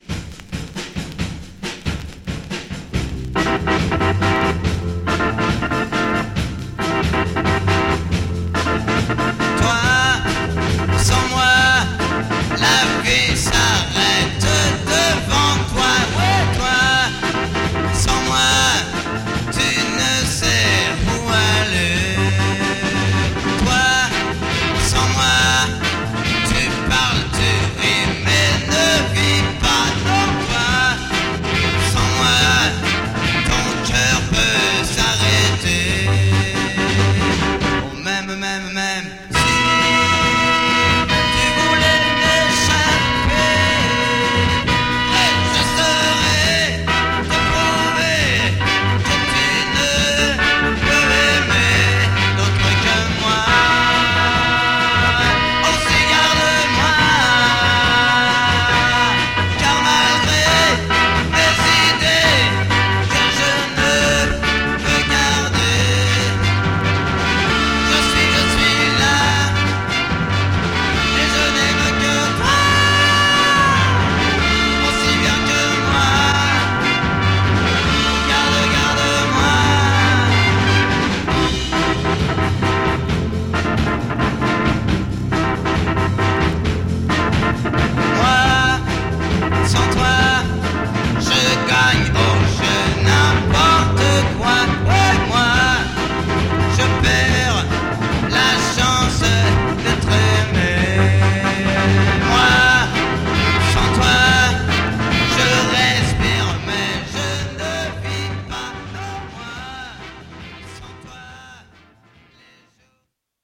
low-fi recording with some nice organ sound